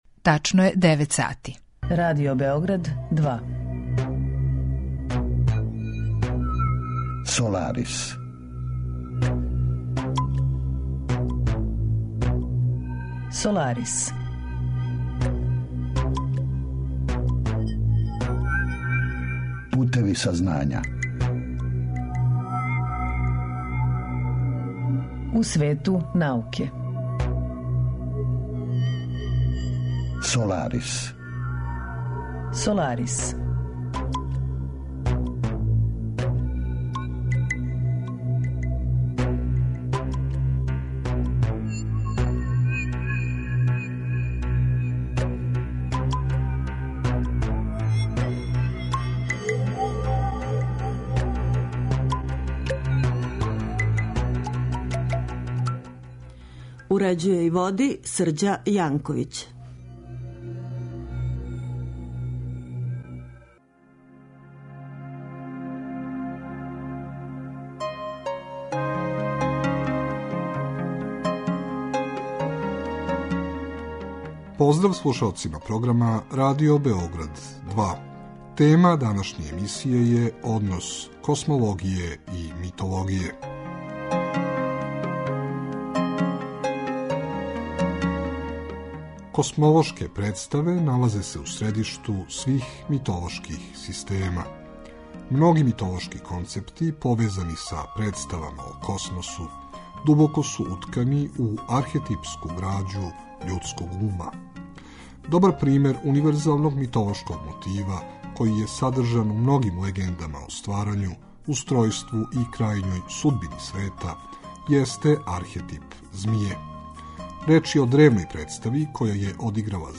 Тема емисије: 'Космологија и митологија', а саговорник